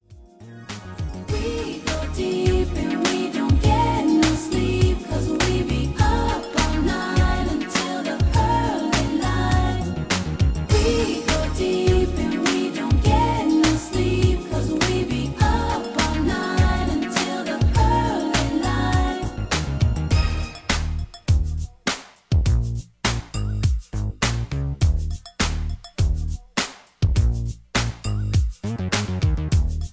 Key-Ebm) Karaoke MP3 Backing Tracks
Just Plain & Simply "GREAT MUSIC" (No Lyrics).
mp3 backing tracks